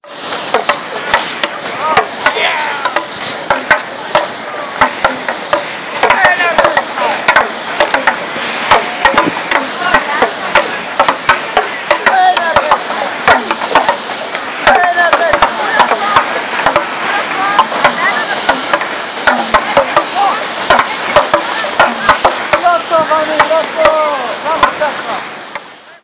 Der Strand bei Sonnenuntergang.
Allgegenwärtig dort: Das Pok-Pok der, uh, Pokpok-Spieler?